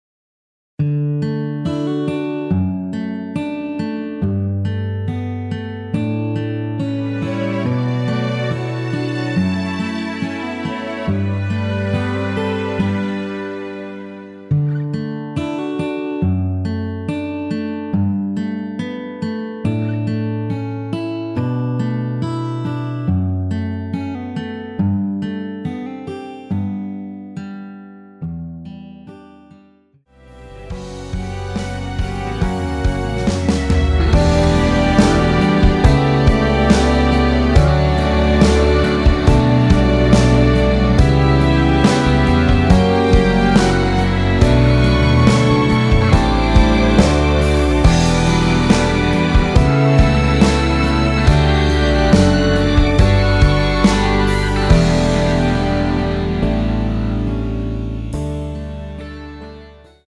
D
앞부분30초, 뒷부분30초씩 편집해서 올려 드리고 있습니다.
중간에 음이 끈어지고 다시 나오는 이유는